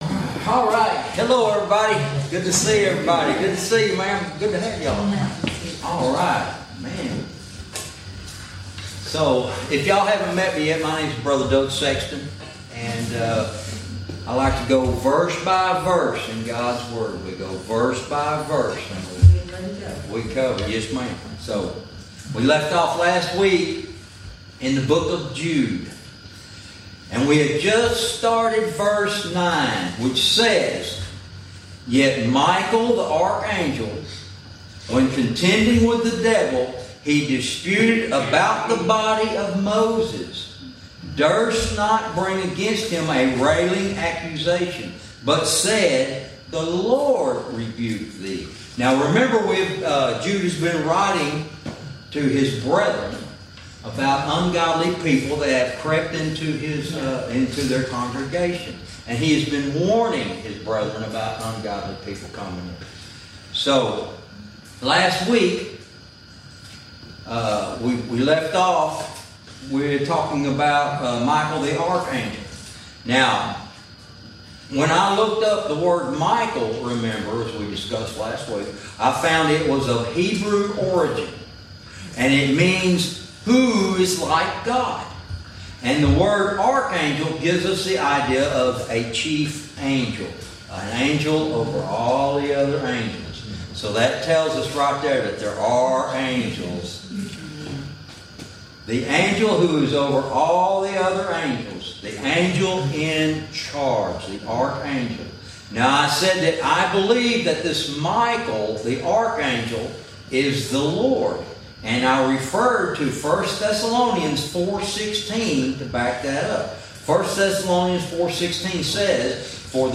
Verse by verse teaching - Lesson 30